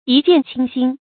一见倾心 yī jiàn qīng xīn 成语解释 倾心：一心向往。
成语繁体 一見傾心 成语简拼 yjqx 成语注音 ㄧ ㄐㄧㄢˋ ㄑㄧㄥ ㄒㄧㄣ 常用程度 常用成语 感情色彩 中性成语 成语用法 连动式；作谓语、定语、宾语；含褒义 成语结构 连动式成语 产生年代 古代成语 成语正音 见，不能读作“xiàn”。